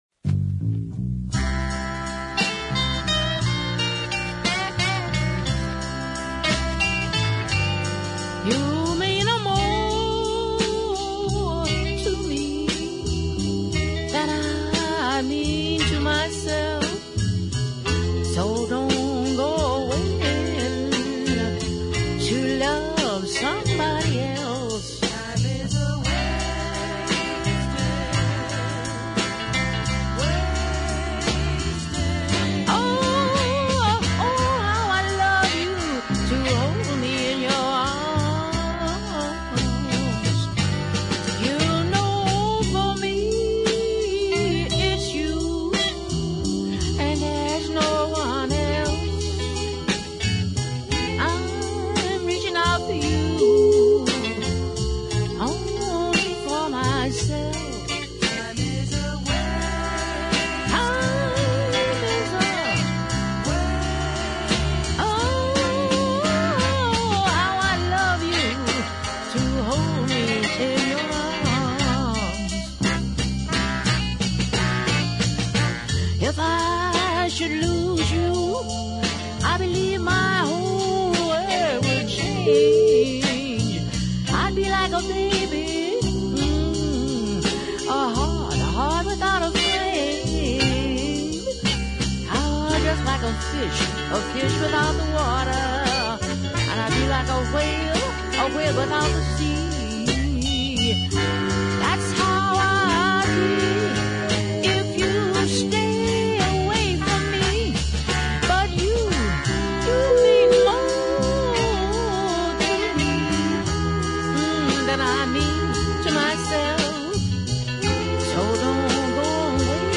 bluesy voiced
classy guitar lines and well structured horn support
low toned world weary vocal